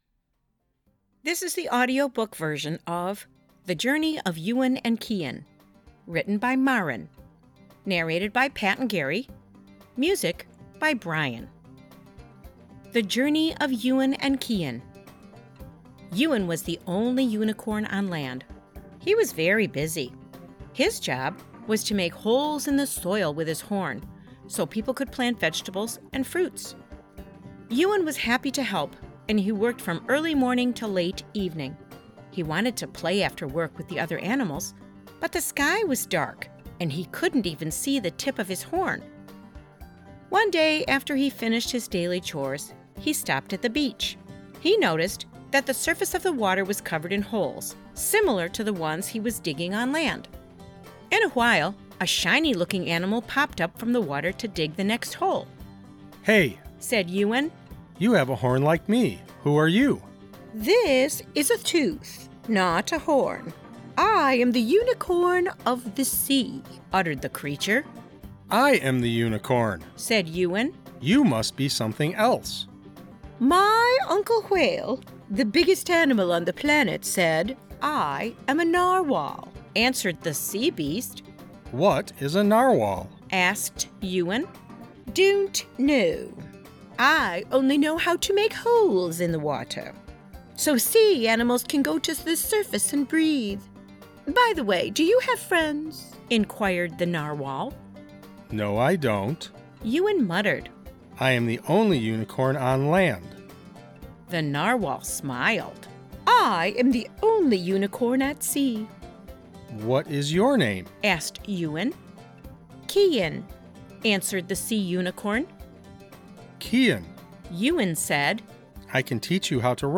Reading The Journey of Yuan and Kian